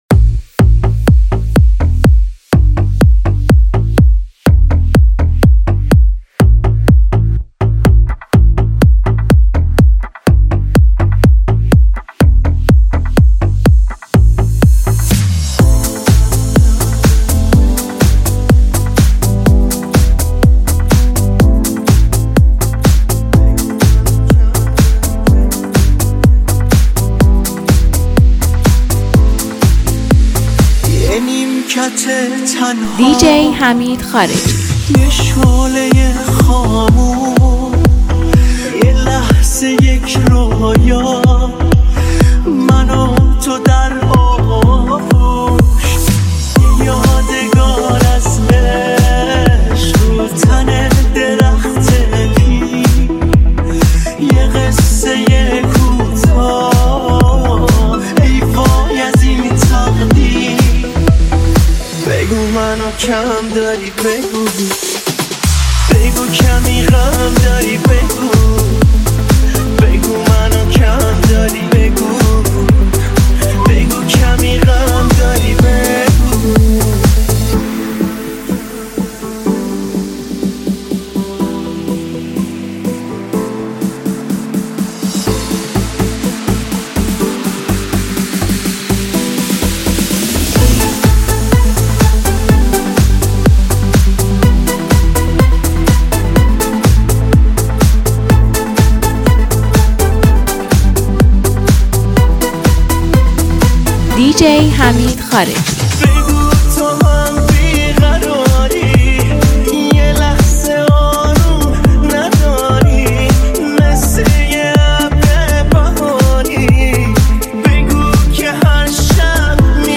این ریمیکس جذاب و پرانرژی
یک ریمیکس شاد و پرطرفدار